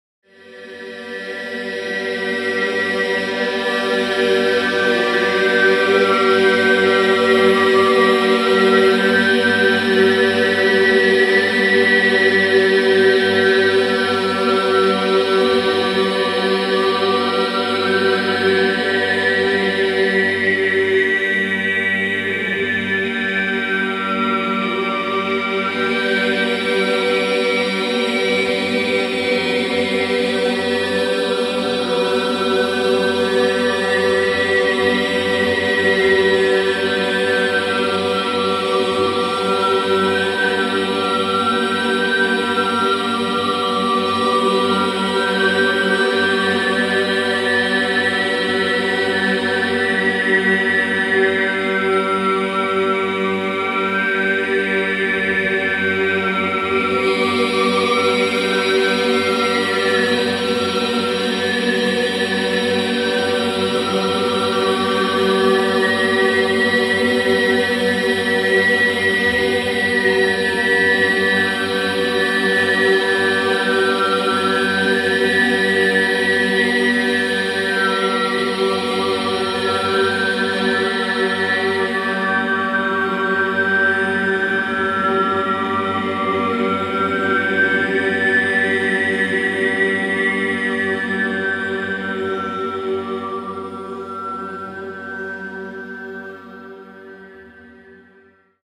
Qui potrai ascoltare alcune parti dei lavori che faremo all'interno del seminario, la durata dei brani è di circa due minuti cadauna
VOCALIZZAZIONI MATTINA-PARTE DI I.mp3